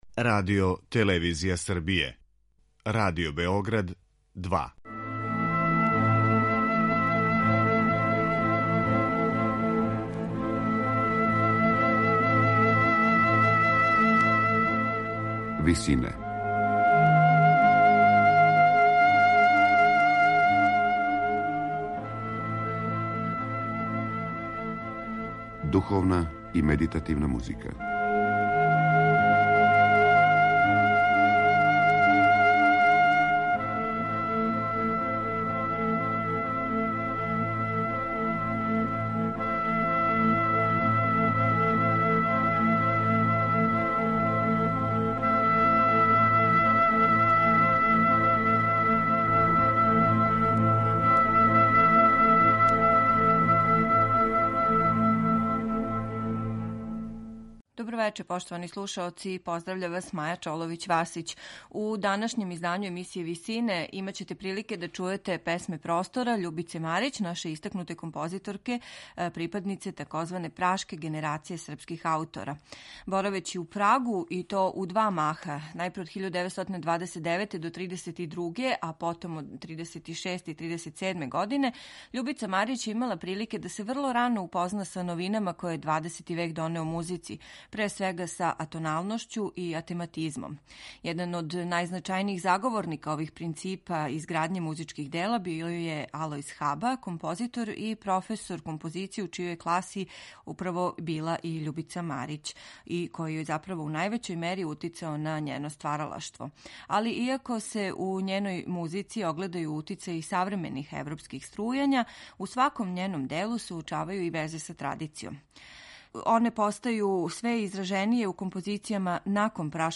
Реч је о Кантати за мешовити хор и симфонијски оркестар